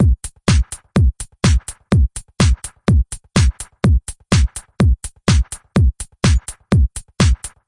声学套件001
描述：在Hammerhead鼓机软件上使用的原声鼓组。
标签： 125 bpm Acoustic Loops Drum Loops 330.82 KB wav Key : Unknown
声道立体声